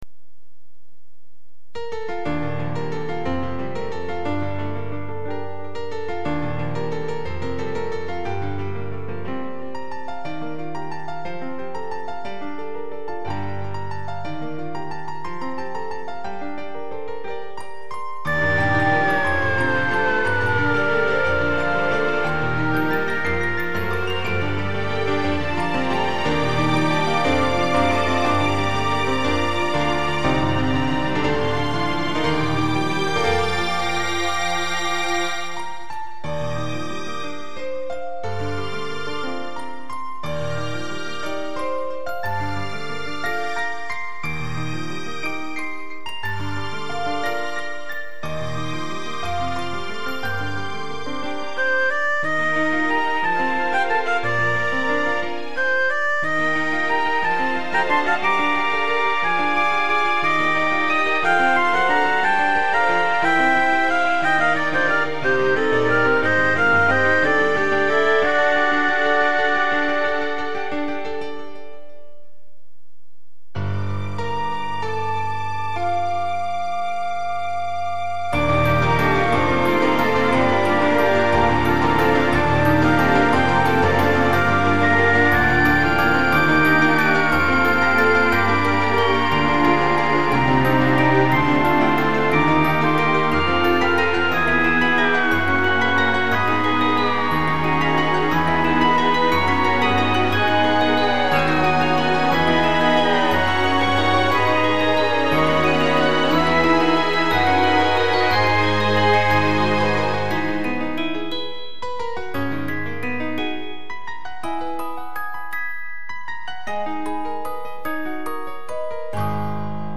泣き声のようなヴァイオリンが特徴。
00:00 分類 インストゥルメンタル